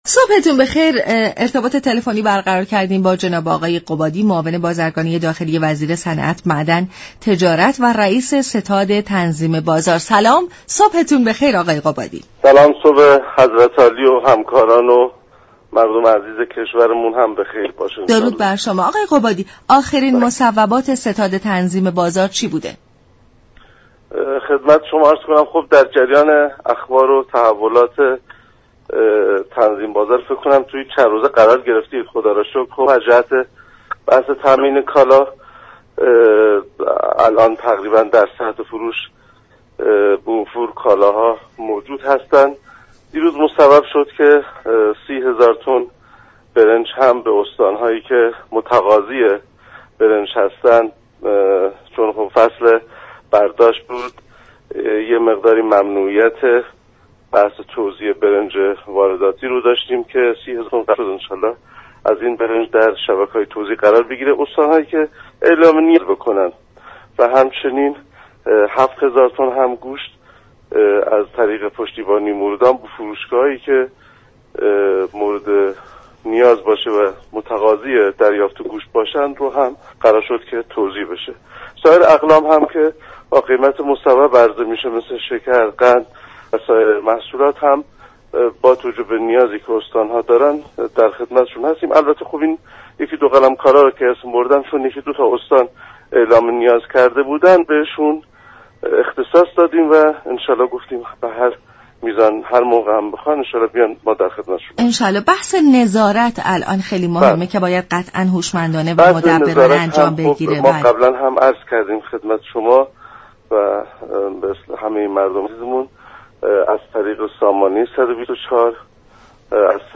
رییس ستاد تنظیم بازار در گفت و گو با رادیو ایران گفت: سامانه پیامكی 124 به دستور وزیر صمت، از 7 صبح تا 10 شب آماده دریافت و رسیدگی به شكایات و اعتراضات مردمی است.